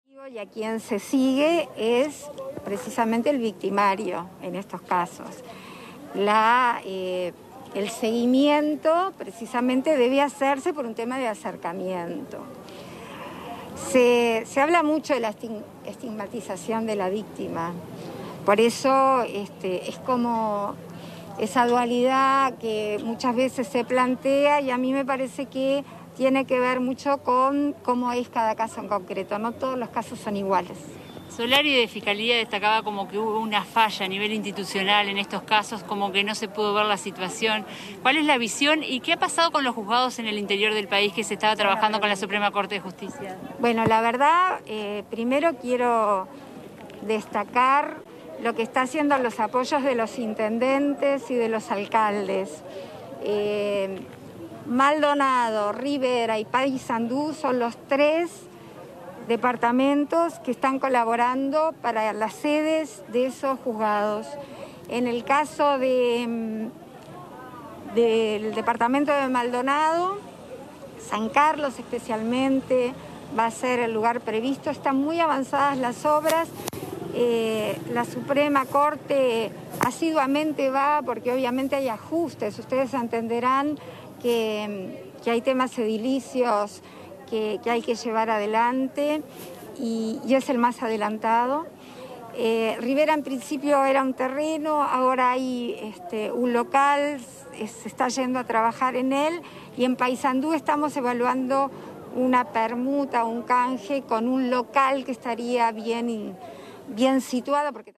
La vicepresidenta de la República, Beatriz Argimón, realizó declaraciones sobre el femicidio ocurrido en Young, el domingo 20 de junio. Las mismas se dieron en una rueda de prensa, en el barrio Maracaná, en el marco de un vacunatorio móvil, para embarazadas y adolescentes entre 10 y 12 años, sin agenda.